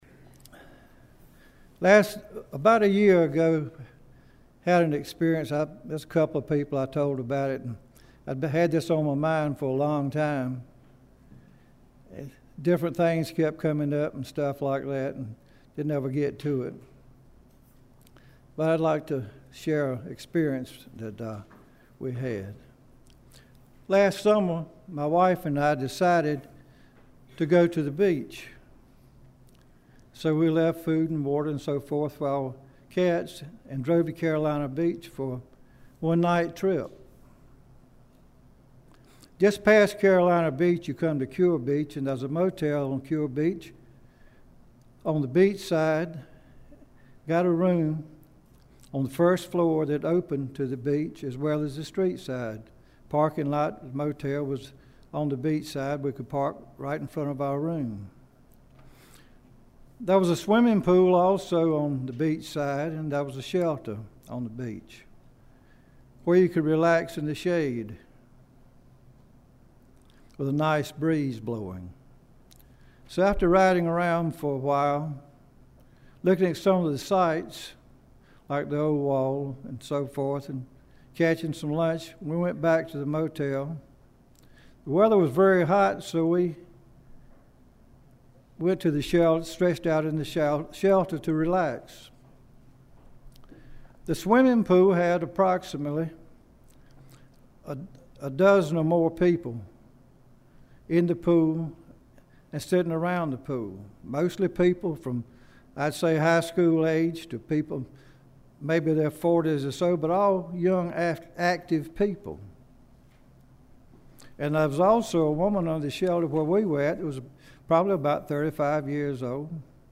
8-6-sermon.mp3